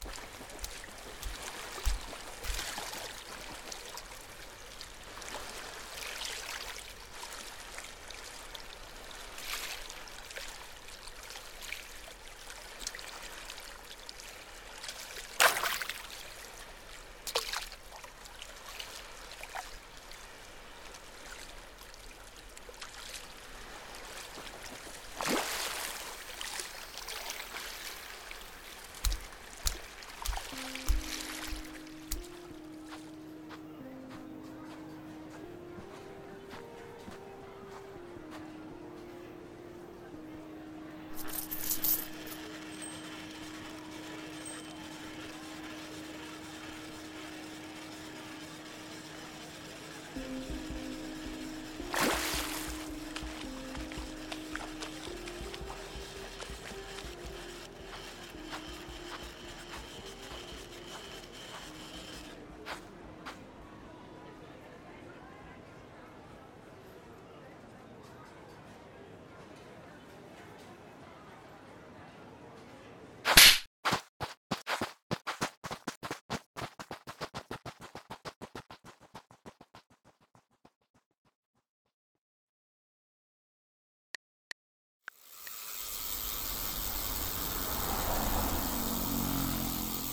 【声劇】夏泳の宙へ。